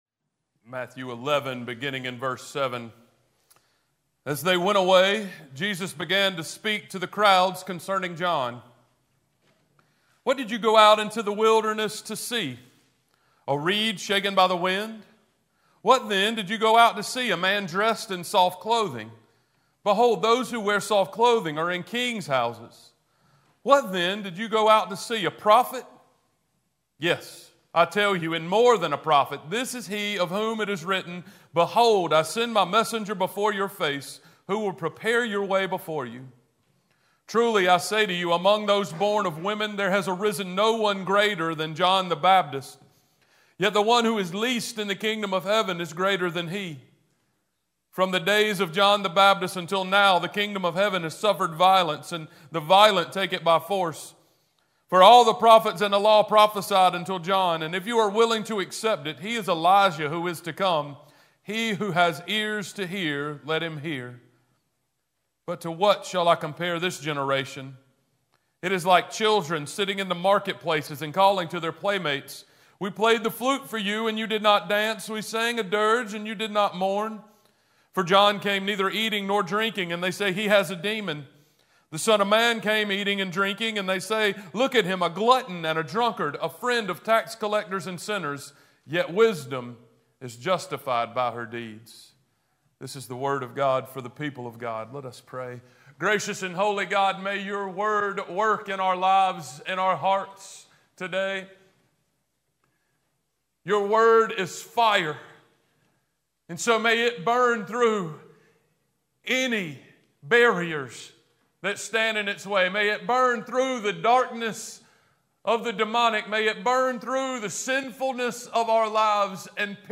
Passage: Matthew 11: 7-19 Service Type: Sunday Worship
Sermon-12-1-24.mp3